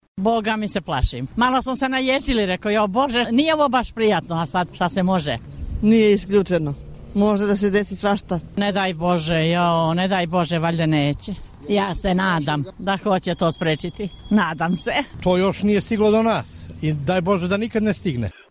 Anketa: Zika virus